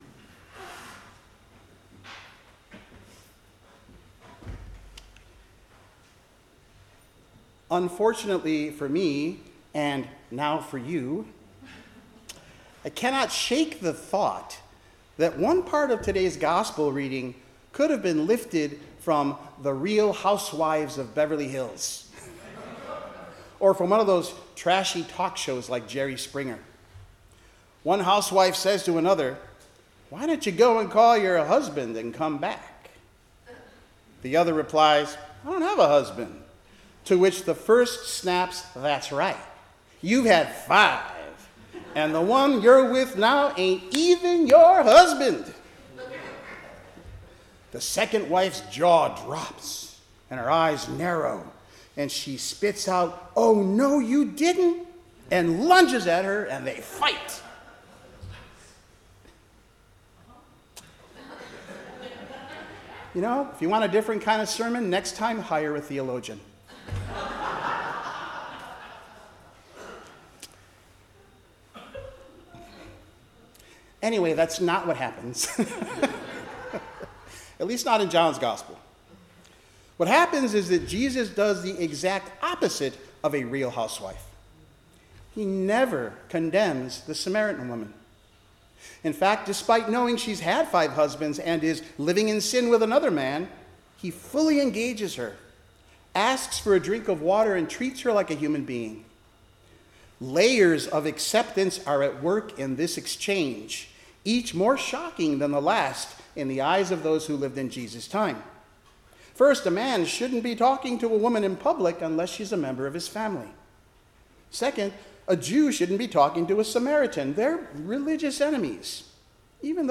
Service Type: 10:00 am Service
Sermon-Third-Sunday-in-Lent-March-8-2026.mp3